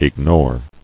[ig·nore]